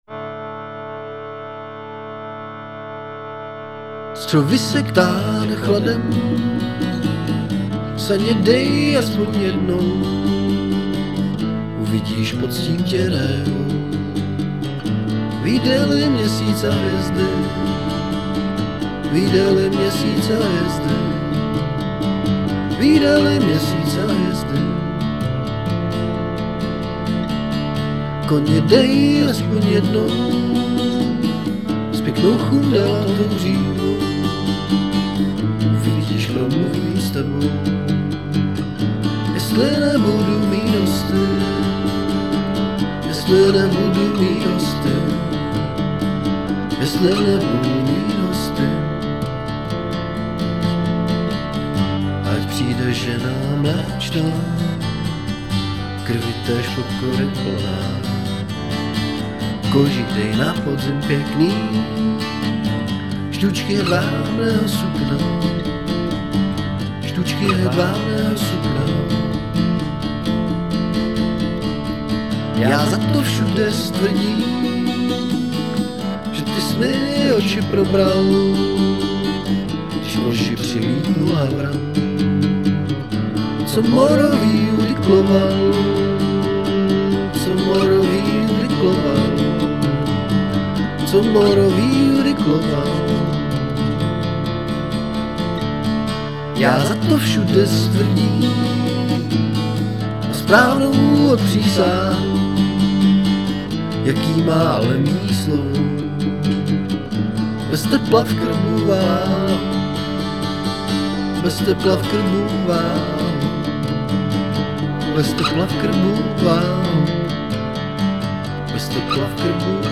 voc, g
kbd, perc